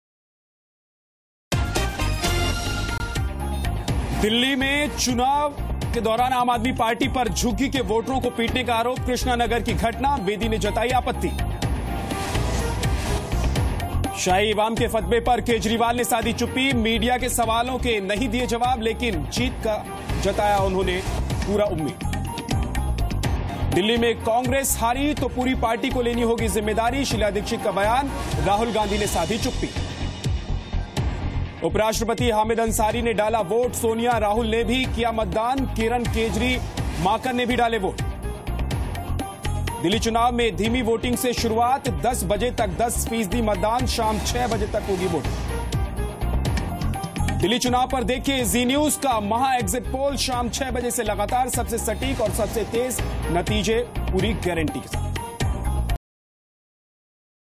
Headlines of the day